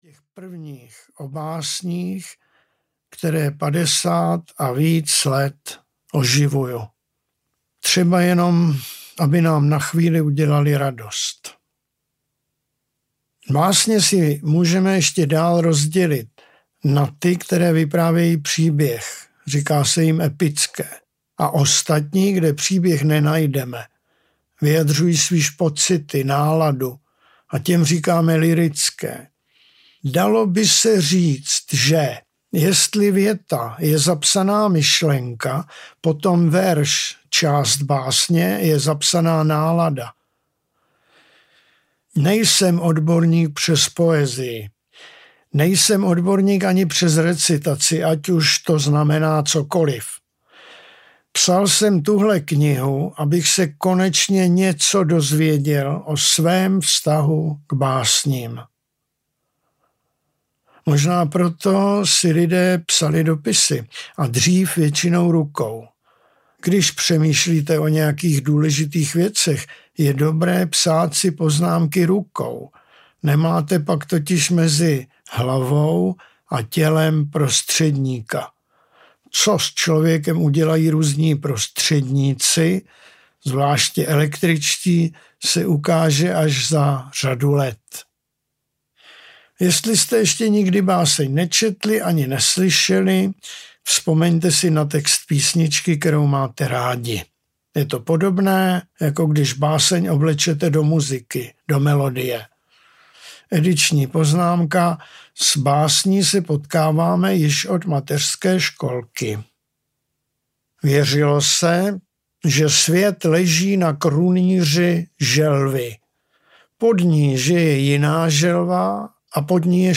Ukázka z knihy
jak-se-rika-basen-audiokniha